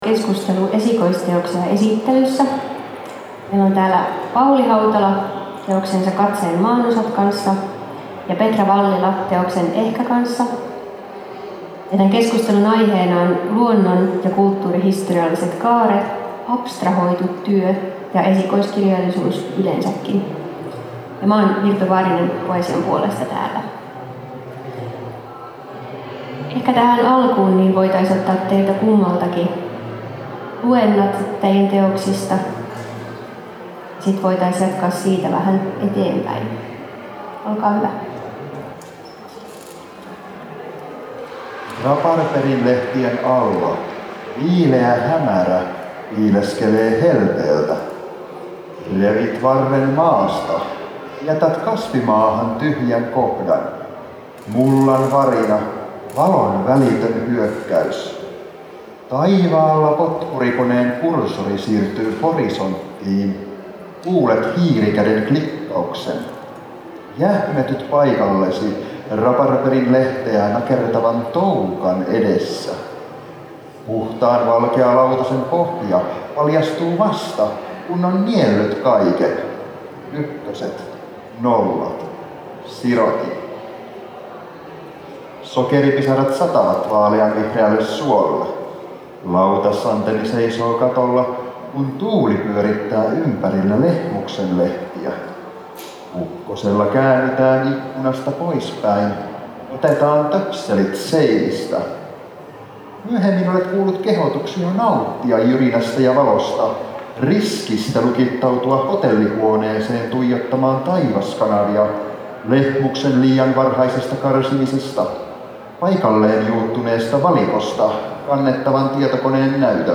2022 Ohjelmataltiointi Turun Kirjamessuilta 2.10.2022 https